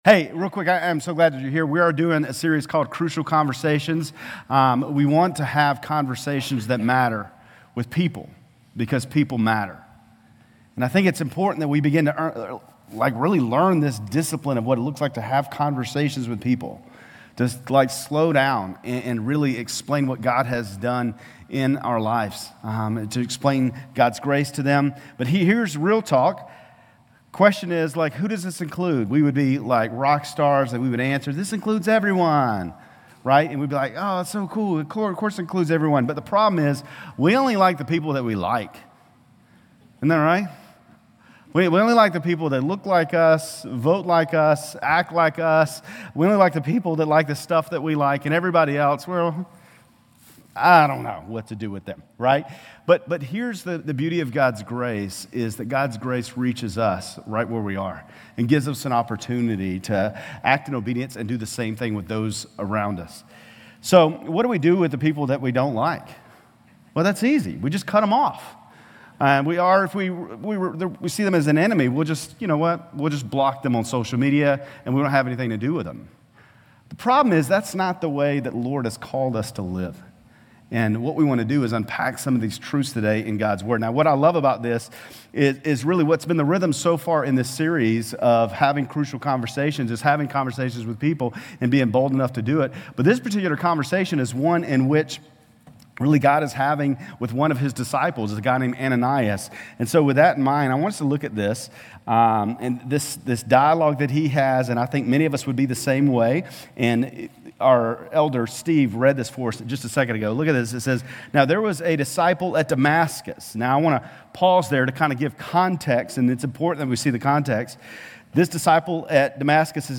GCC-Lindale-October-29-Sermon.mp3